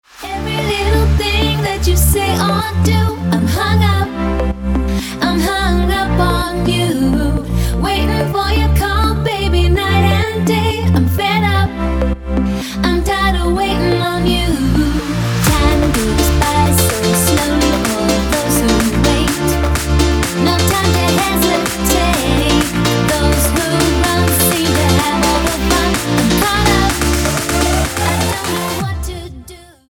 • Качество: 256, Stereo
женский вокал
dance
future house
club
house
vocal